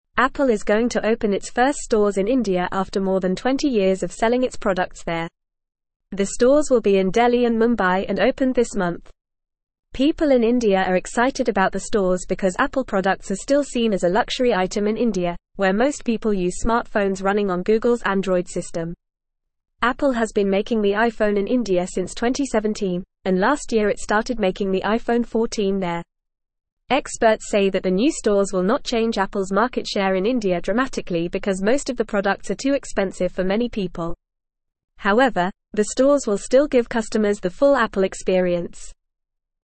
Fast
English-Newsroom-Beginner-FAST-Reading-Apple-to-Open-First-Stores-in-India.mp3